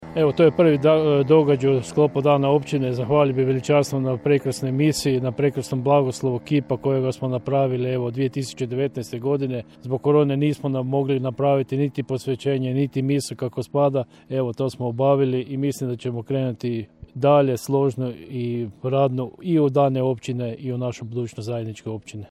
Na obilježavanje se osvrnuo načelnik Miljenko Horvat: